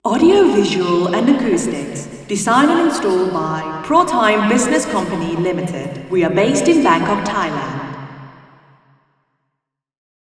Not surprisingly, this placement produces some audible flutter echoes, but the improvement in SI is striking.
Receiver 02     STI = 0.67
RIR_MFA_W_A1_02_Female_Anechoic_Speech_MONO.wav